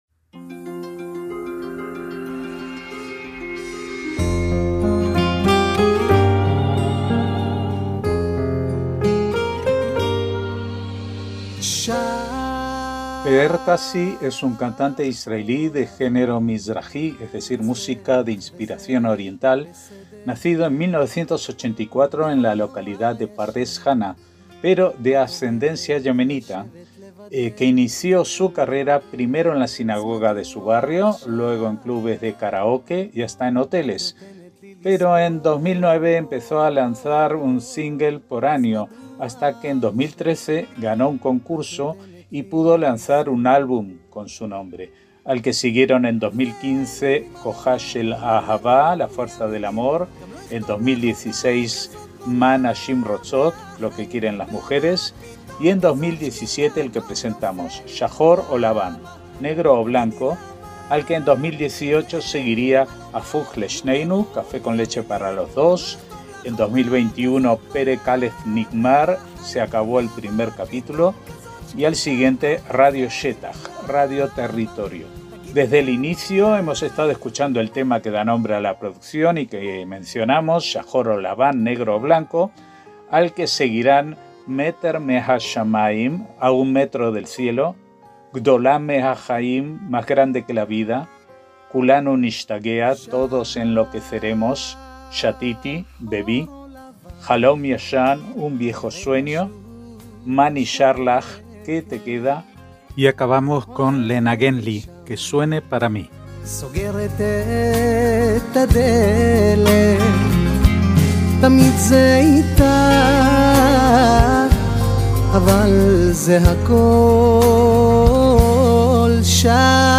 MÚSICA ISRAELÍ